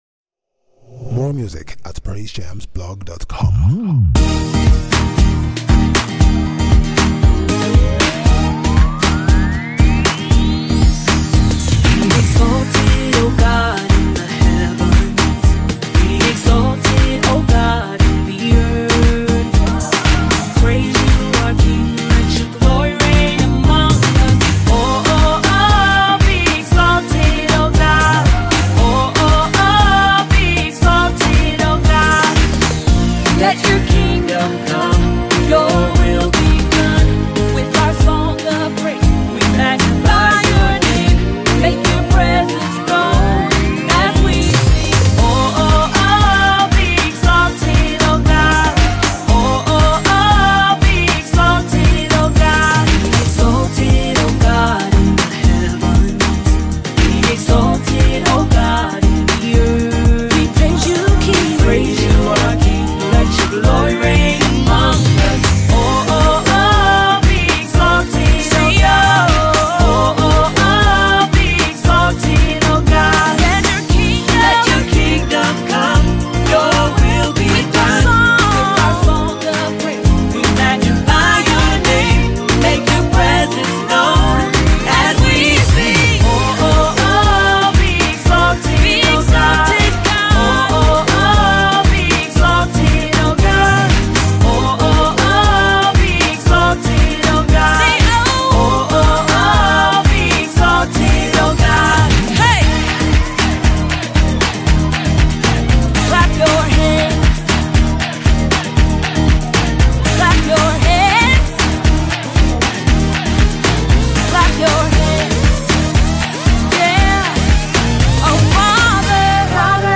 the praise & worship team